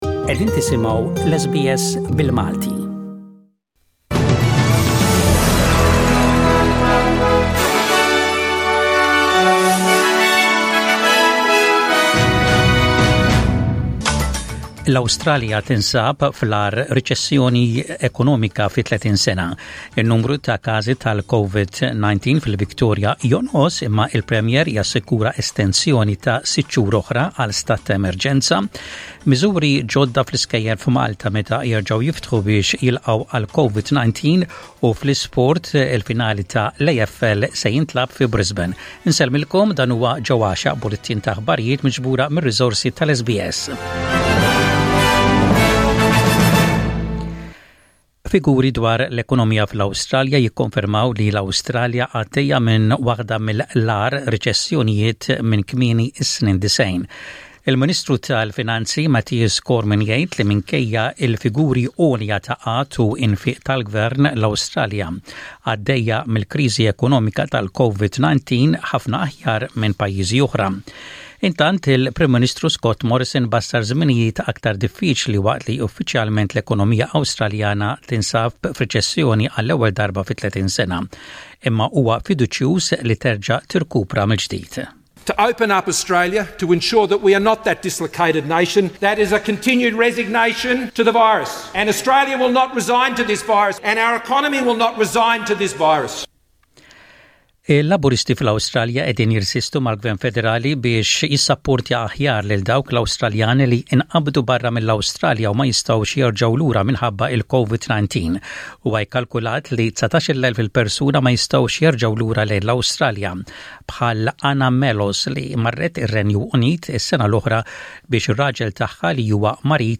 SBS Radio | Maltese News: 04/09/20